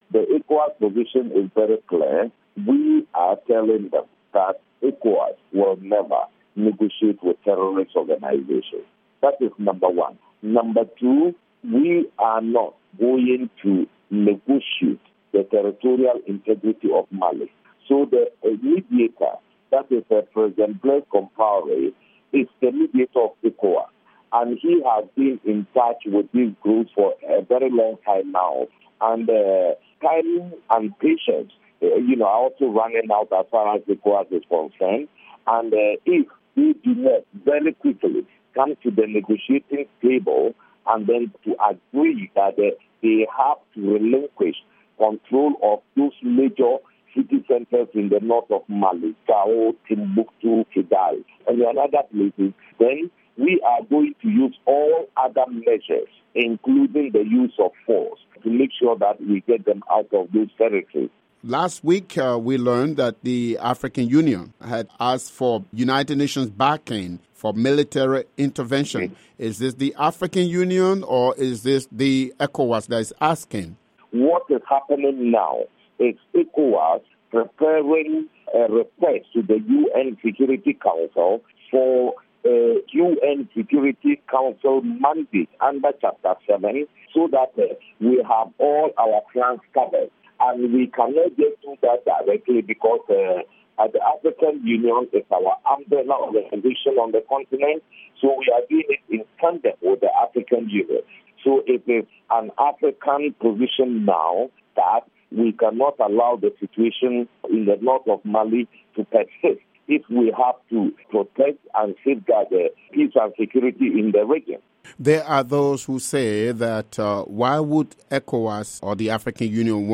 interview with Abdel Fatau Musah of ECOWAS